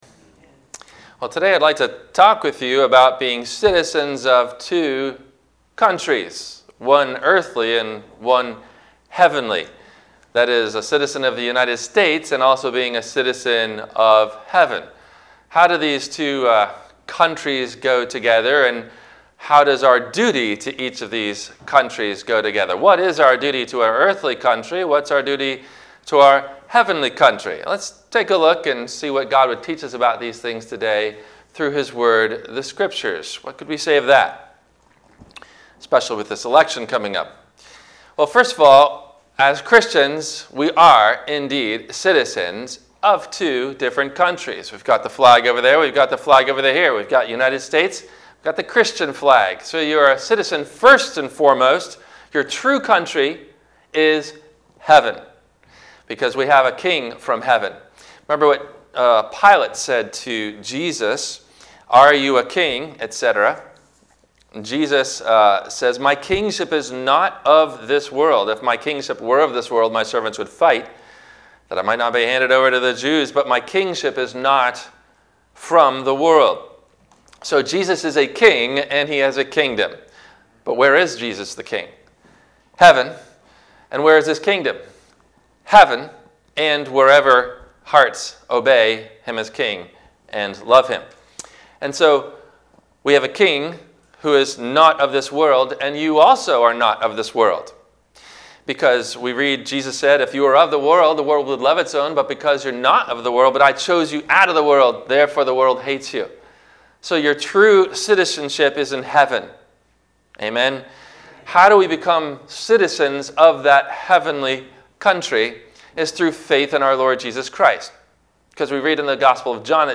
Citizens of Two Countries – WMIE Radio Sermon – November 09 2020
No Questions asked before the Radio Message.